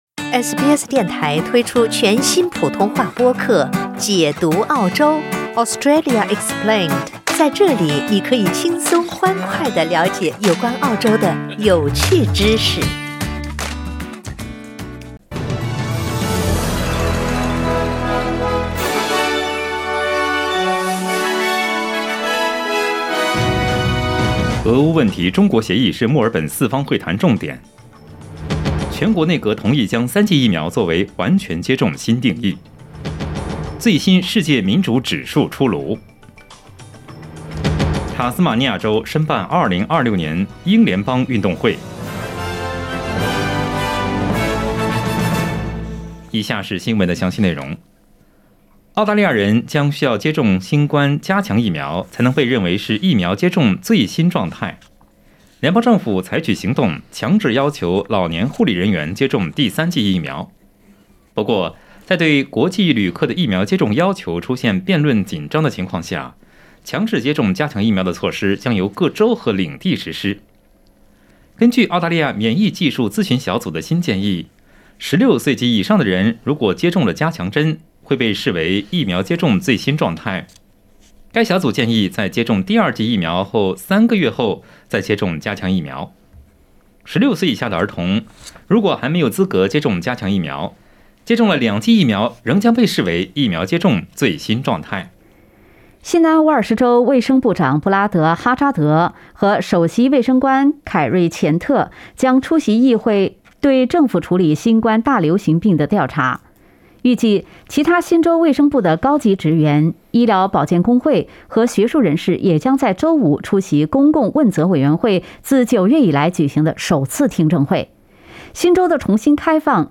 SBS早新闻（2月11日）
SBS Mandarin morning news Source: Getty Images